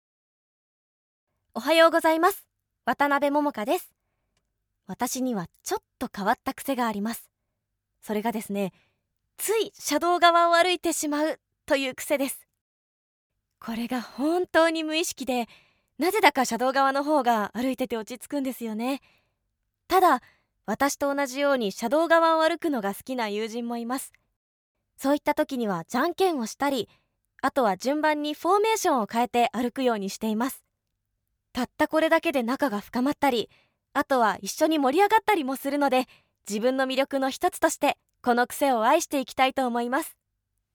Voice Sample
フリートーク